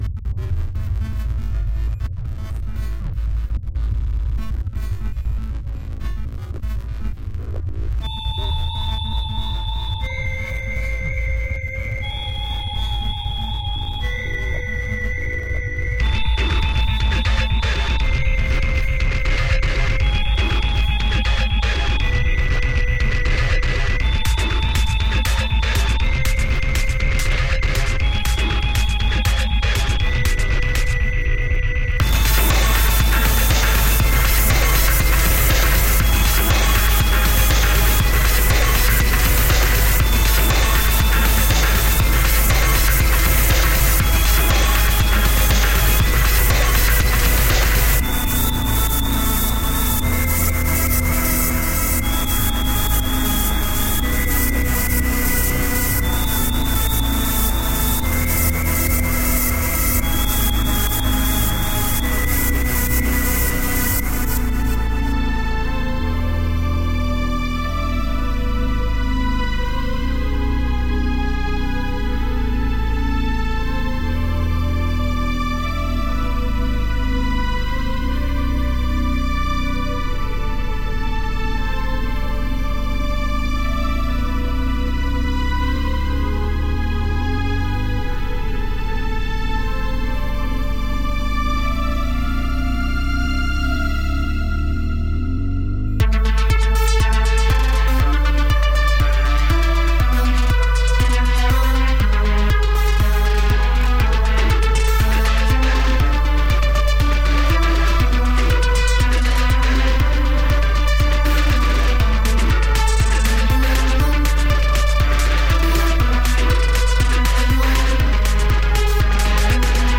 Dark ambient with an alchemical twist.
Tagged as: Electronica, Other